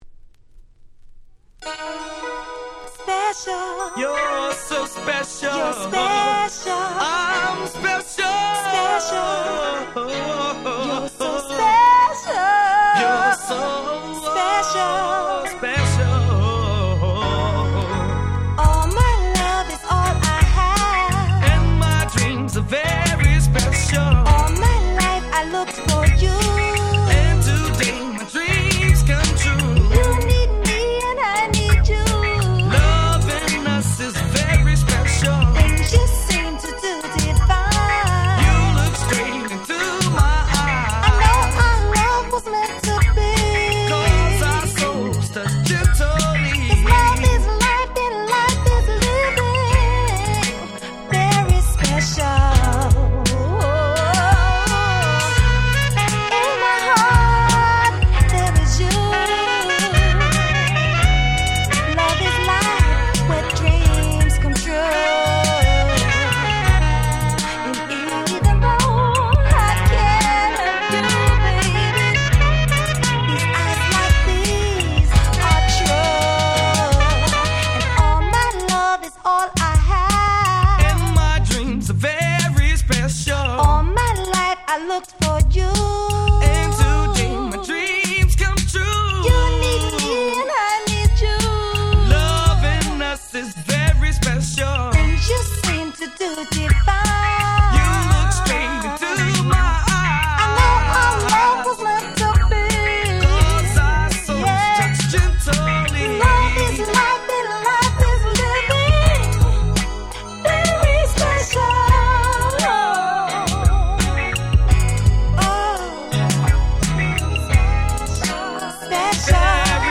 ピコピコした打ち込みが何とも雰囲気出ております！